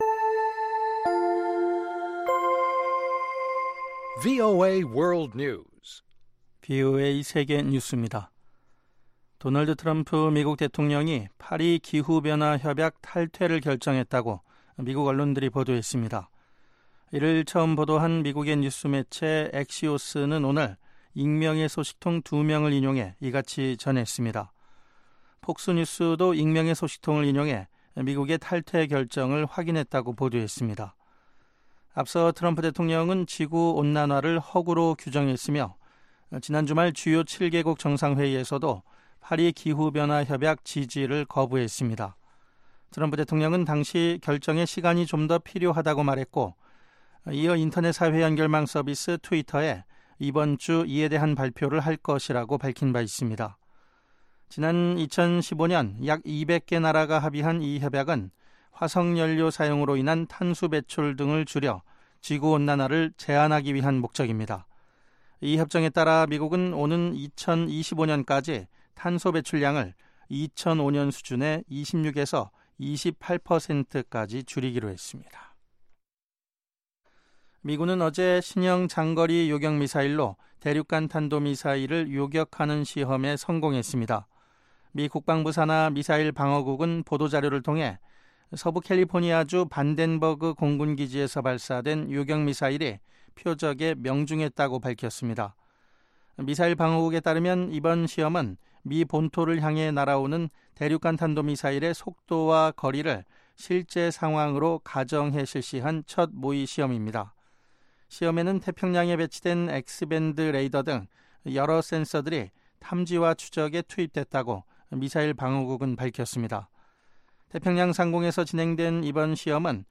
VOA 한국어 방송의 간판 뉴스 프로그램 '뉴스 투데이' 3부입니다. 한반도 시간 매일 오후 11:00 부터 자정 까지, 평양시 오후 10:30 부터 11:30 까지 방송됩니다.